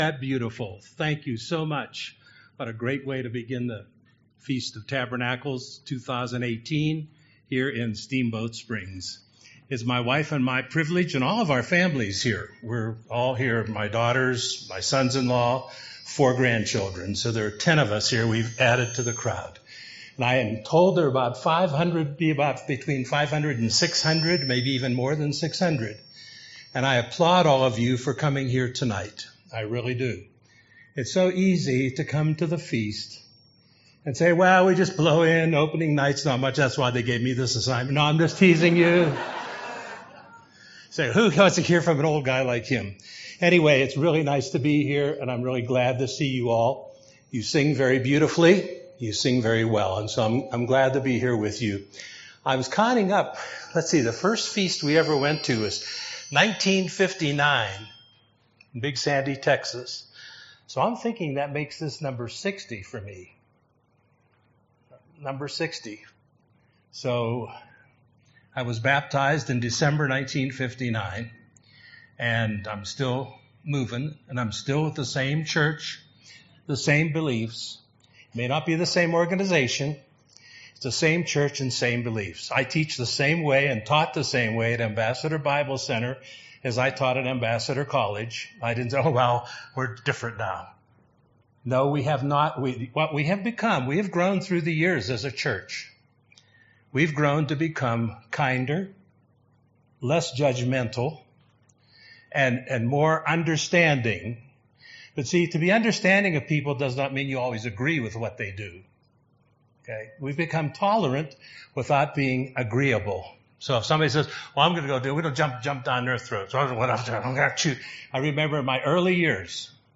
This sermon was given at the Steamboat Springs, Colorado 2018 Feast site.